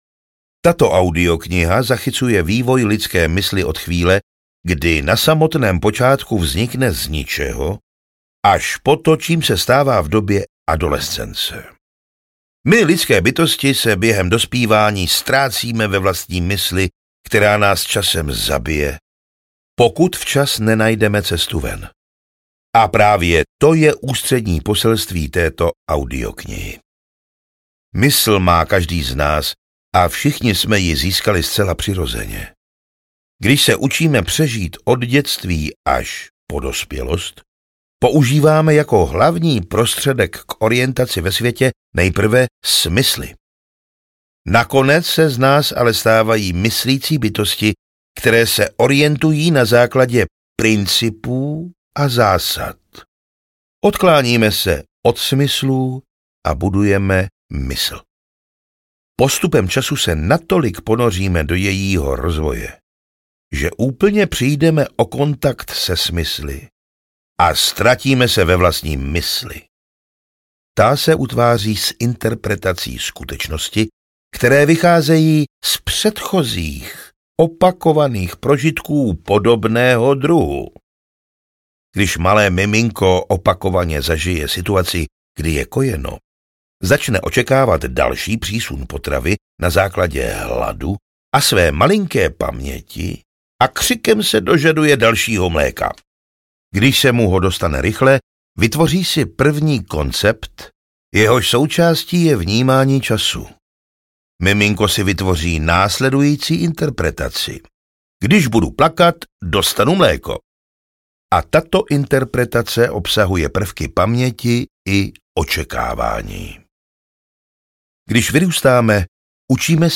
Audiokniha Radikální upřímnost - Brad Blanton | ProgresGuru